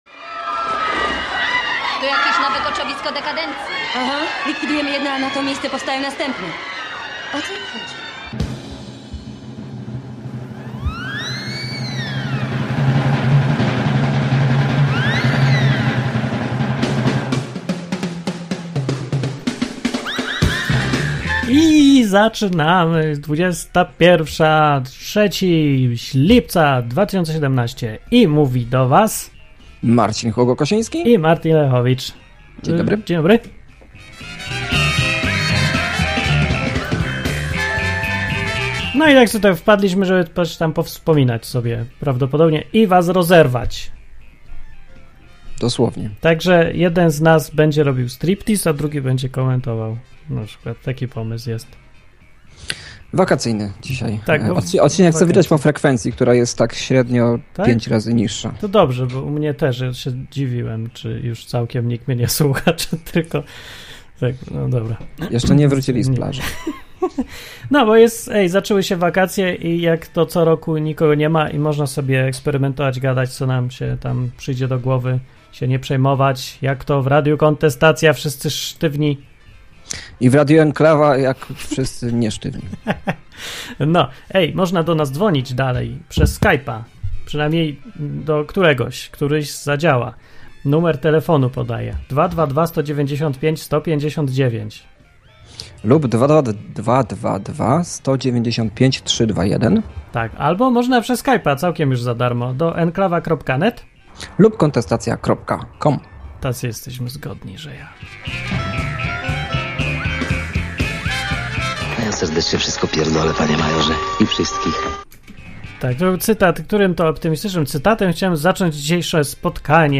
Free! na żywo! Program dla wszystkich, którzy lubią luźne, dzikie, improwizowane audycje na żywo.